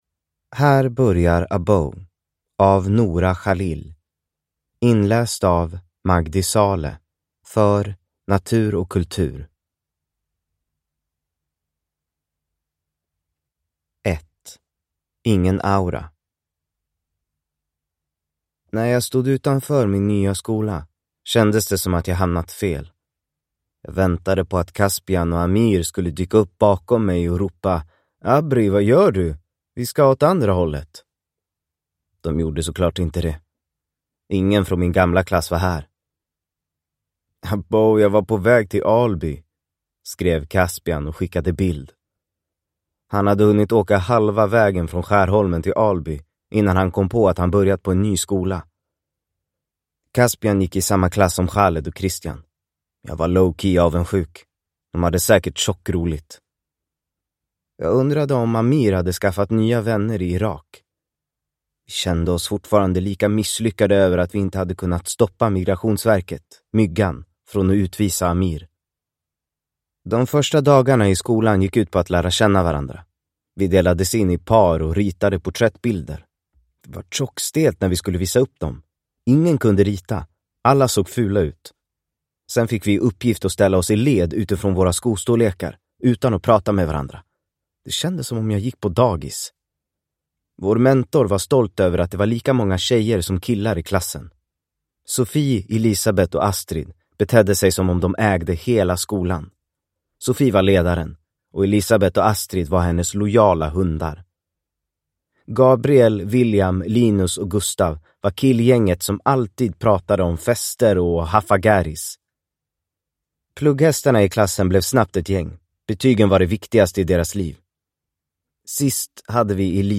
Abow (ljudbok) av Nora Khalil